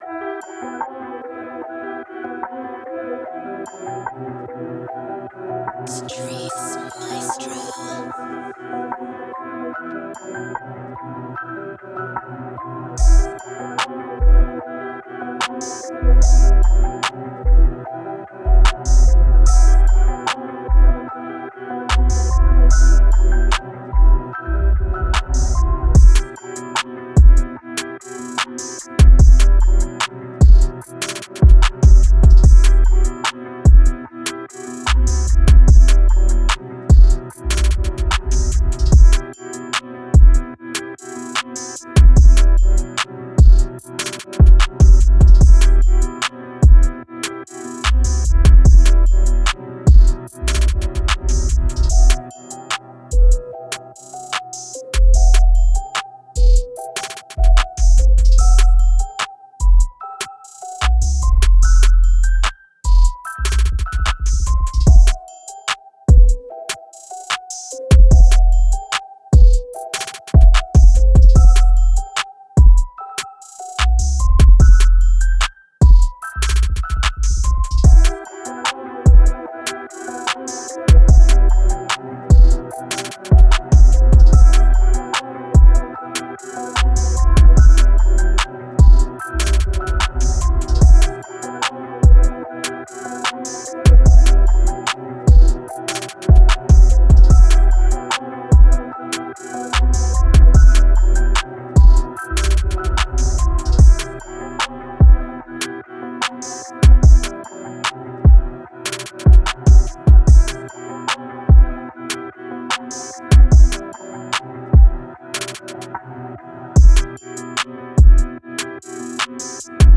Hard Type Beat
Moods: hard, dark, dramatic
Genre: Rap
Tempo: 148